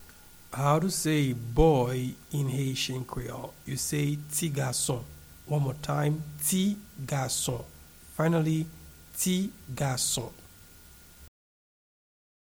Pronunciation and Transcript:
Boy-in-Haitian-Creole-Ti-gason-pronunciation.mp3